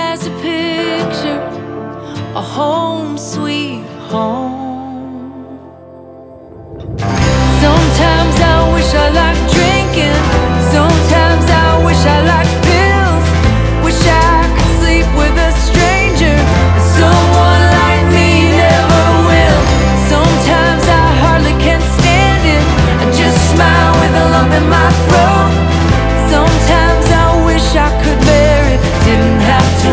Genre: Country